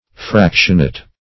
Fractionate \Frac"tion*ate\, v. t.